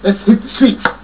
SPEECH FROM THE MOVIE :